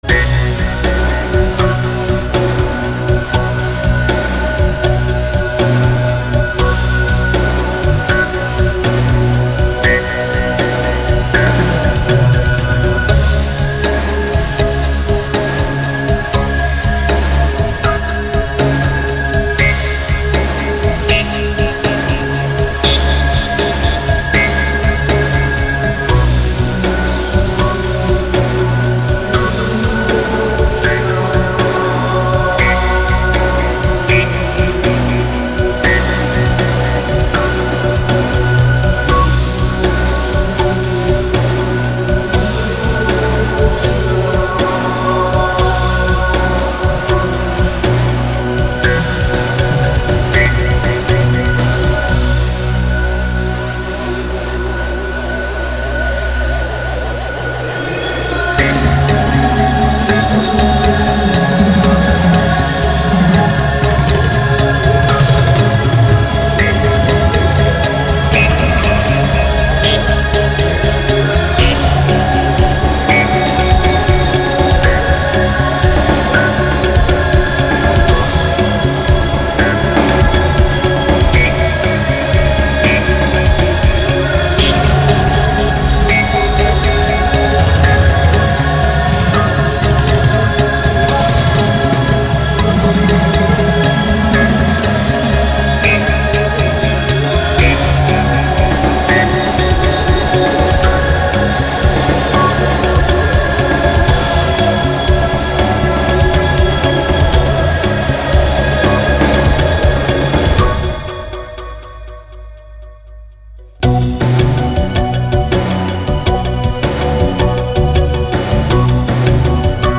Progressive Rock
Studioproduktion | Dortmund